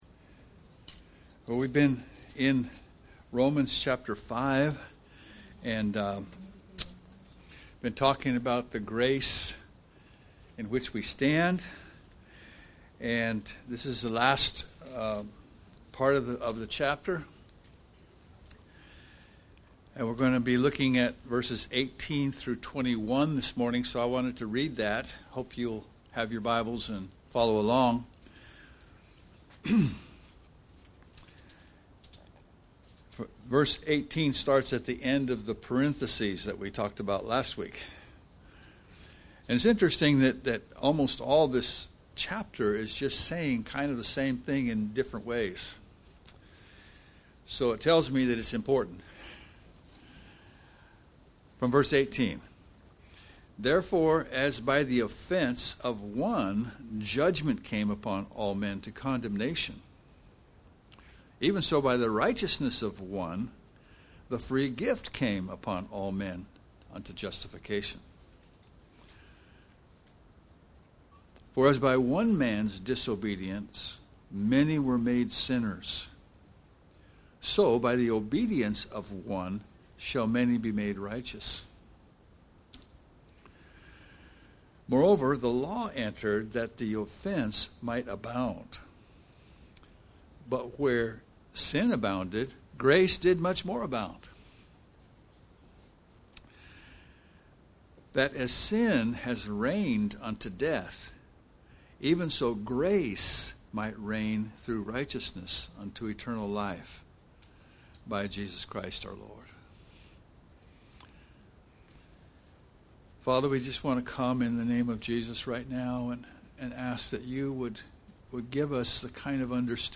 Romans Passage: Romans 5:18-21 Service Type: Sunday Morning « Romans 5